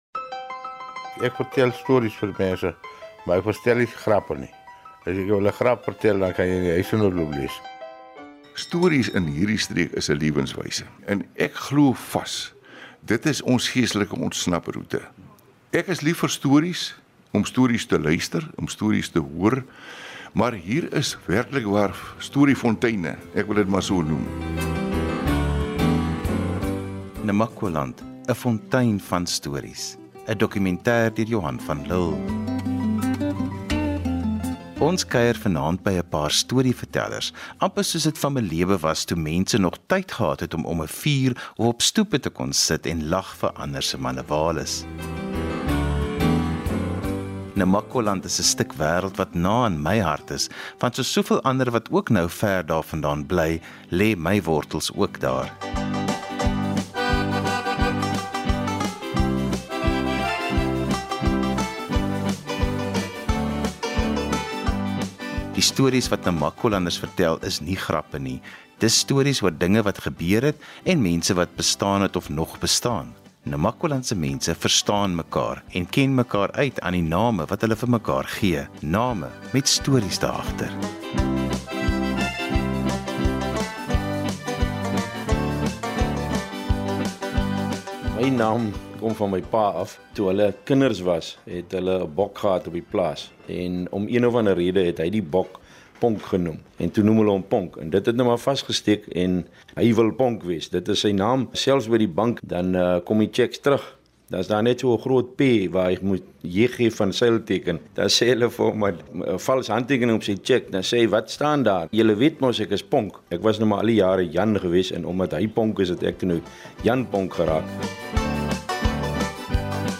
Vir Namakwalanders is stories deel van gesels en kuier. Die namakwalandse storievertellers het hul eie manier om goed te vertel.